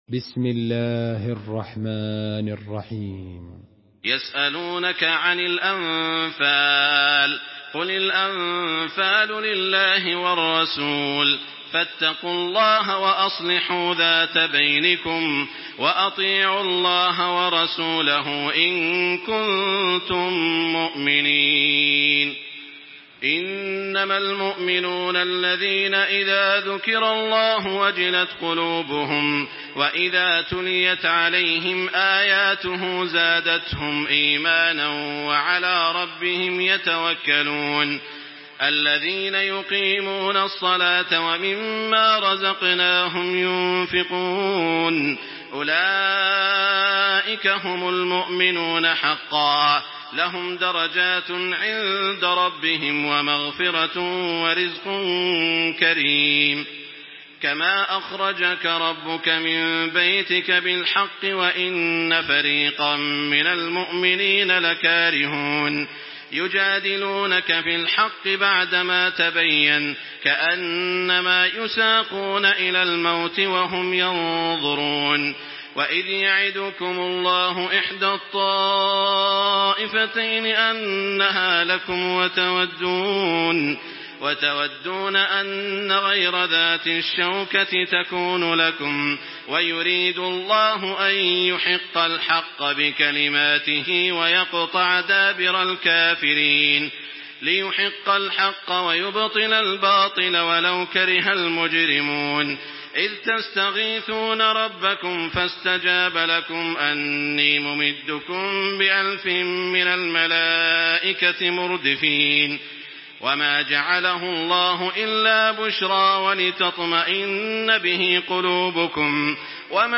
Surah Al-Anfal MP3 in the Voice of Makkah Taraweeh 1426 in Hafs Narration
Listen and download the full recitation in MP3 format via direct and fast links in multiple qualities to your mobile phone.
Murattal